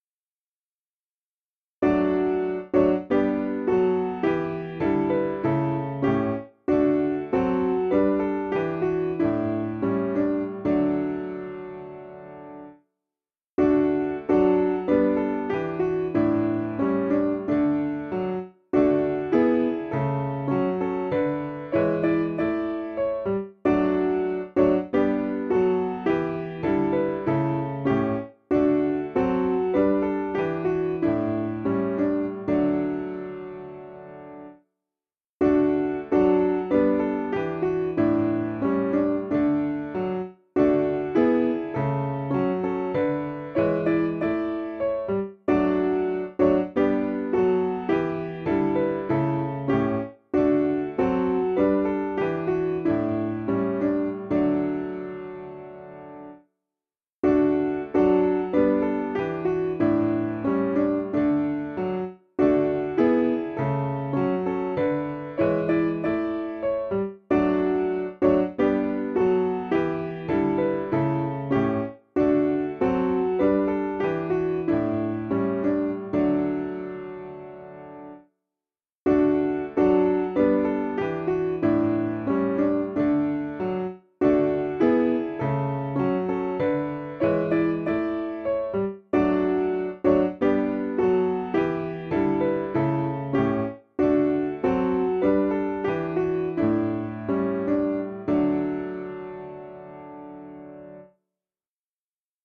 Information about the hymn tune RANDOLPH (Vaughan Williams).